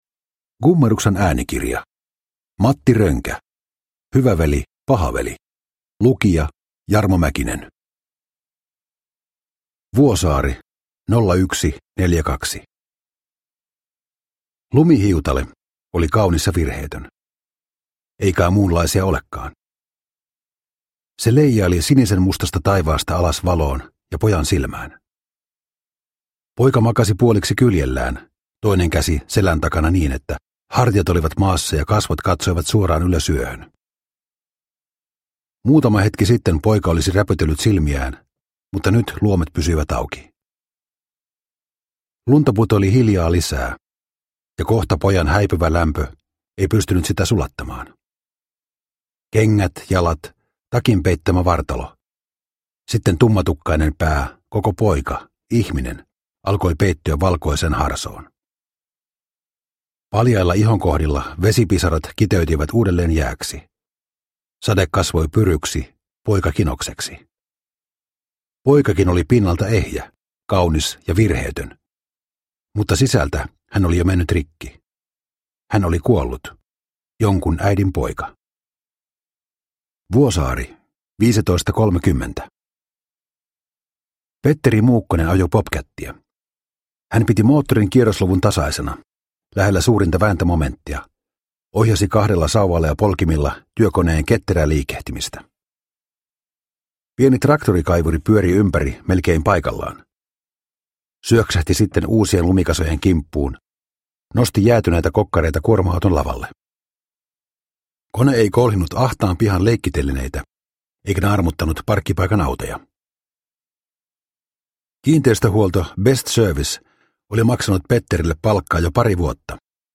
Hyvä veli, paha veli – Ljudbok – Laddas ner